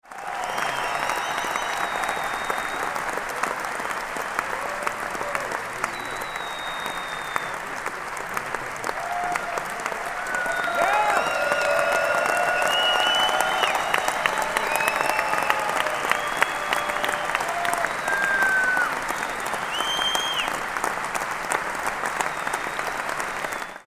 Audience Applause Sound Effect
Loud crowd applause with cheering, shouting, and whistling from excited fans greeting a music band after a live performance.
Audience-applause-sound-effect.mp3